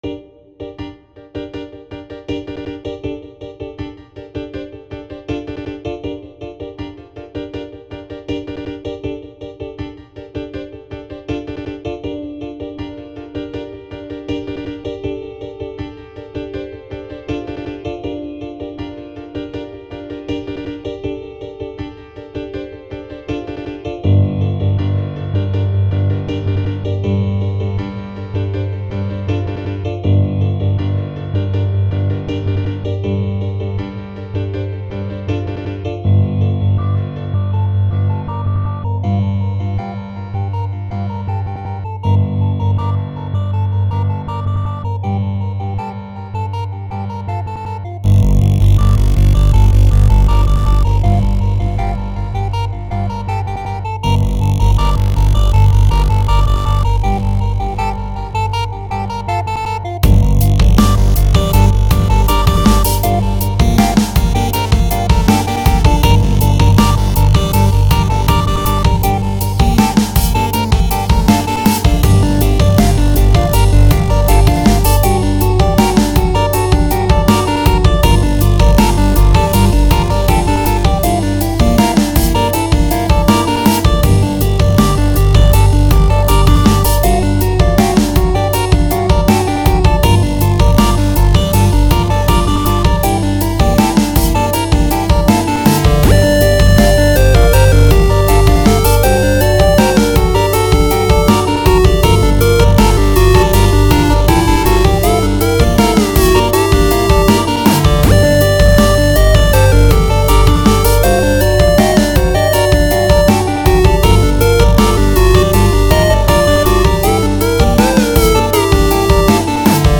Some dark synthy stuff.